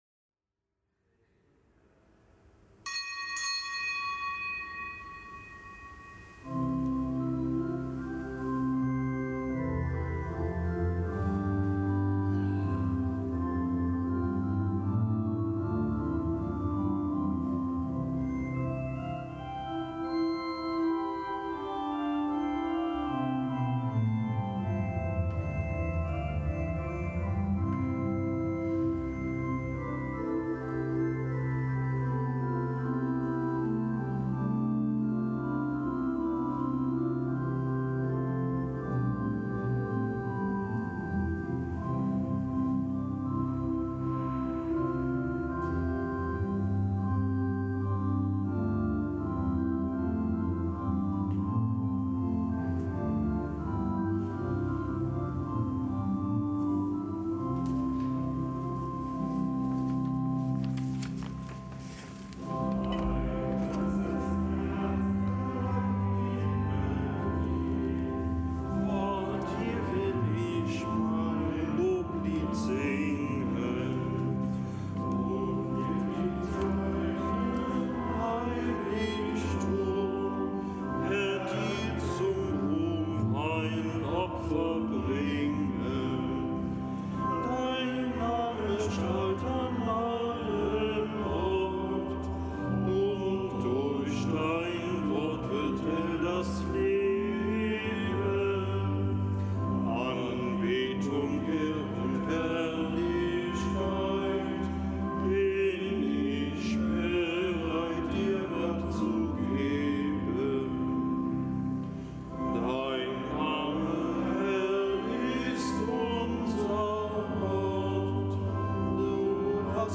Kapitelsmesse am Gedenktag der Heiligen Scholastika
Kapitelsmesse aus dem Kölner Dom am Gedenktag der Heiligen Scholastika, Jungfrau.